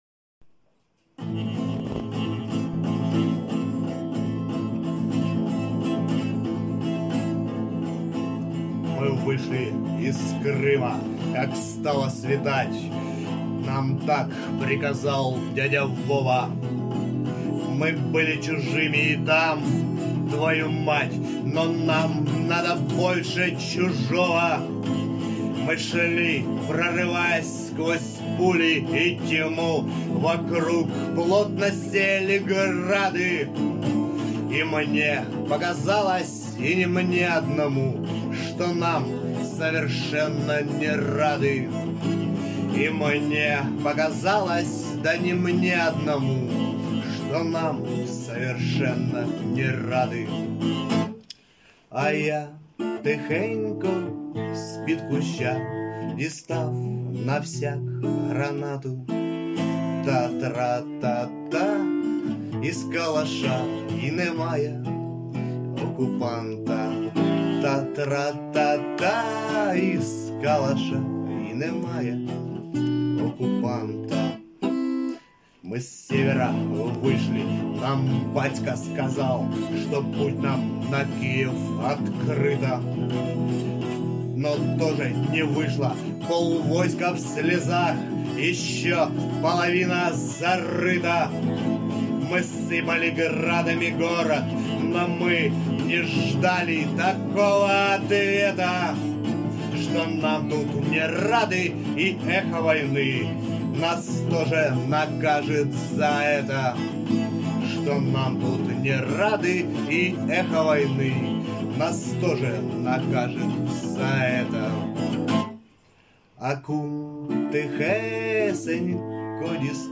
ТИП: Пісня
СТИЛЬОВІ ЖАНРИ: Епічний